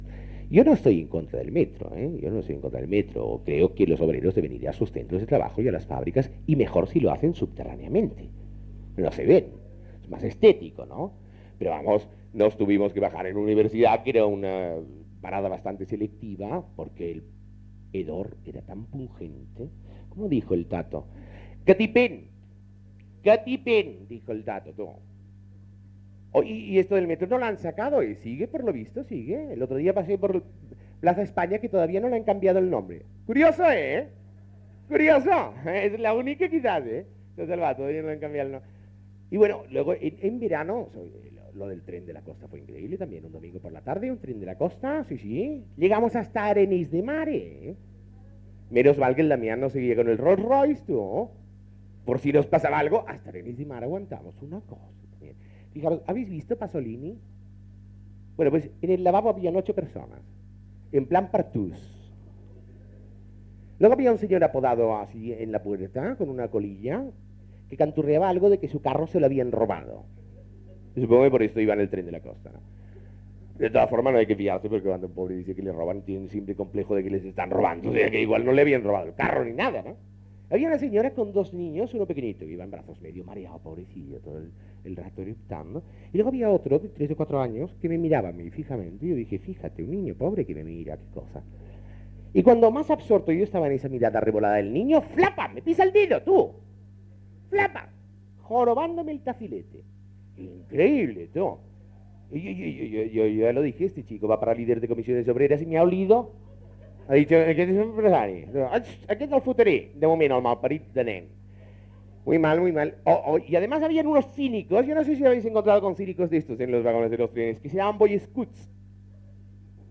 Entreteniment
La Parrila del Ritz era un espai de l'Hotel Ritz de Barcelona, on es feien actuacions.